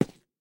immersive-sounds / sound / footsteps / rails / rails-04.ogg
rails-04.ogg